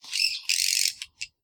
sounds_bat_01.ogg